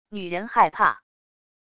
女人害怕wav音频